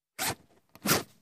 Index of /server/sound/clothing_system/fastener